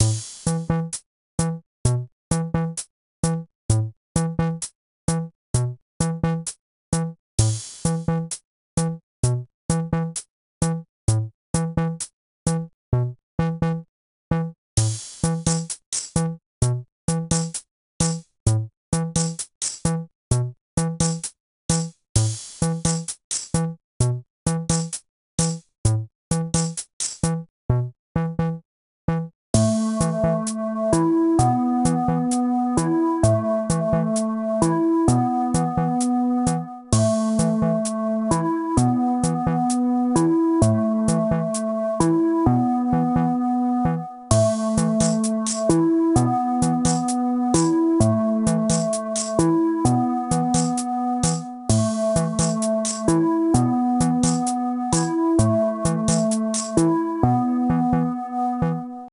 A calm electronic piece that reminds me of waiting in line.